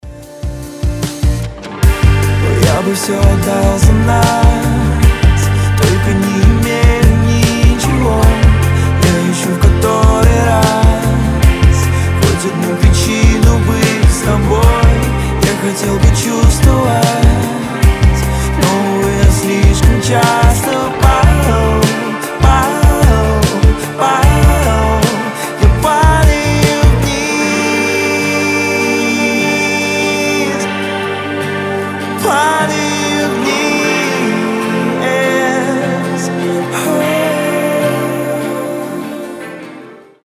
• Качество: 320, Stereo
гитара
грустные
атмосферные
медленные